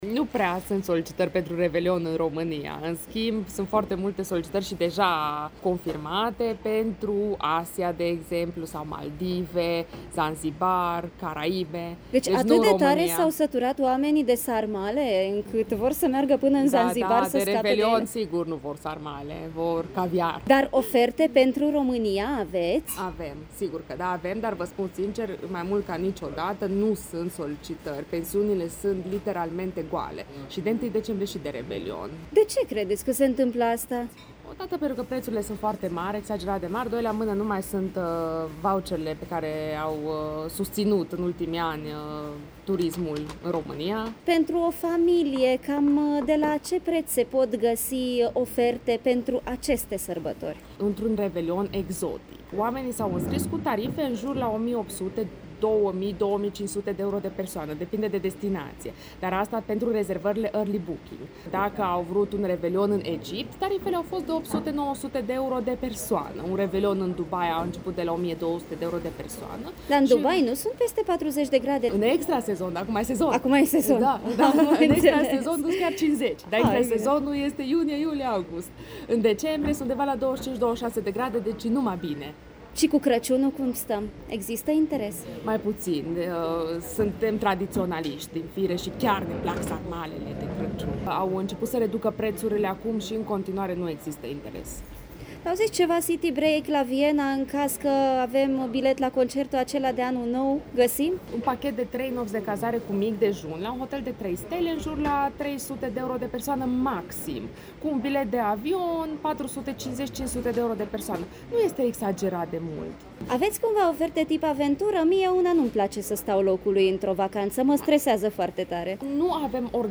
Am fost și noi la fața locului, în căutarea răspunsului la întrebarea care devine inevitabilă în perioada asta: ce facem de Revelion?